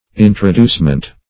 Introducement \In`tro*duce"ment\